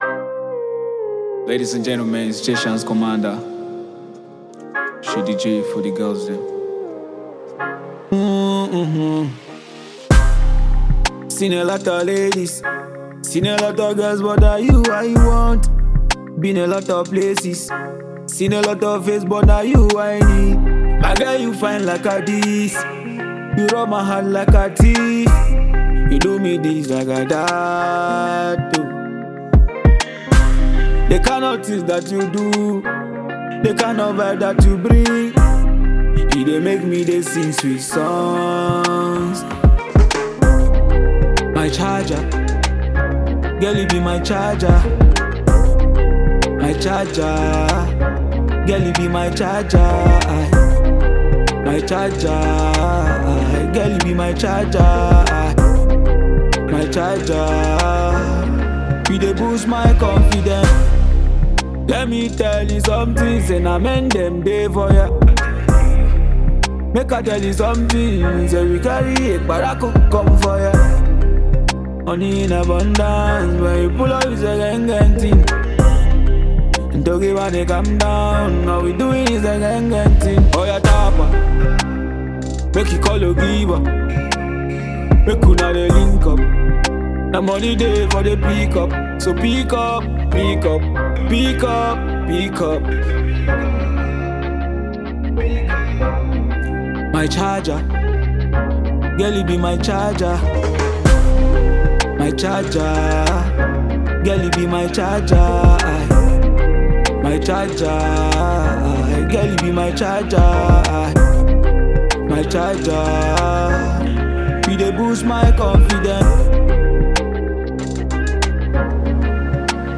Afro pop
love song
Quality Sound, Great Lyrics and passive flow